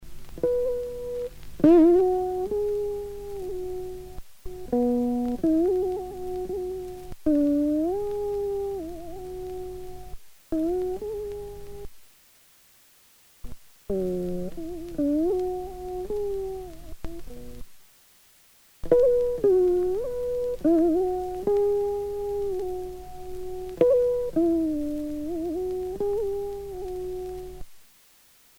enfantine : berceuse